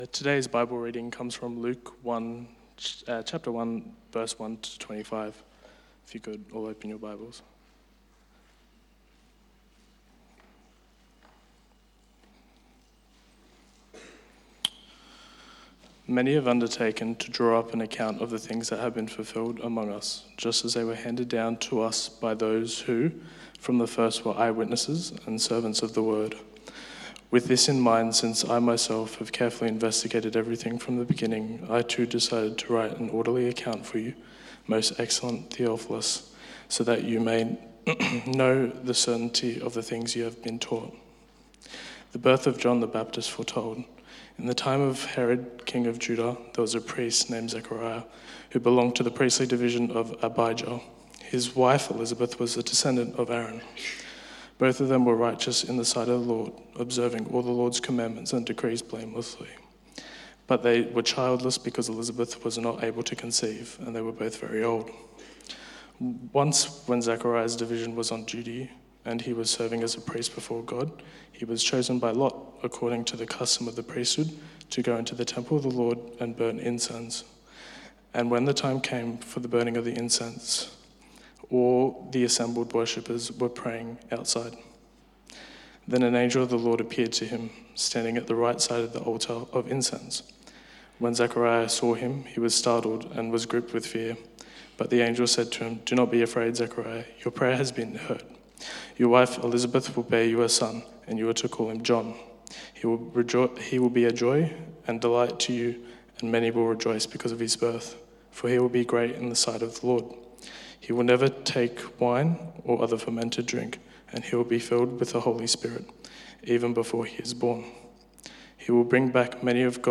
Luke 1:1-25 Service Type: 6PM Advent has begun.